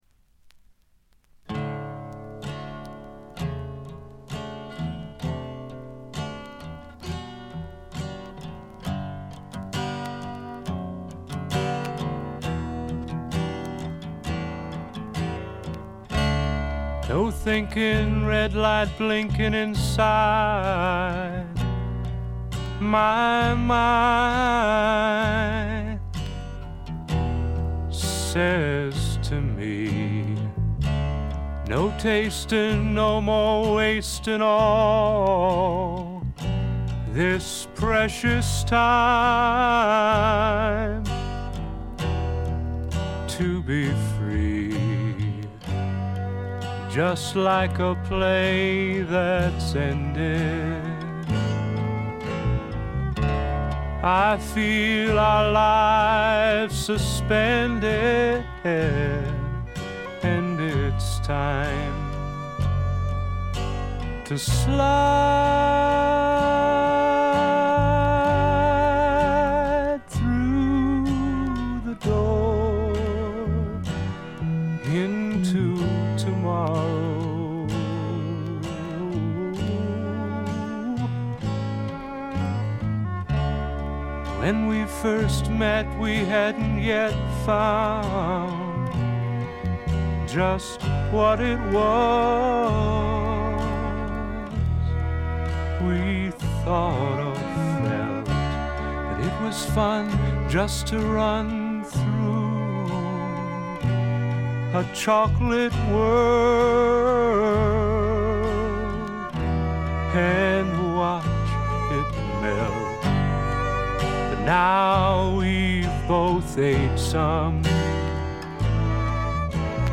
ホーム > レコード：英国 SSW / フォークロック
静音部でチリプチが聞かれますが気になるノイズはありません。
静と動の対比も見事でフォークロック好きにとってはこたえられない作品に仕上がっています！
試聴曲は現品からの取り込み音源です。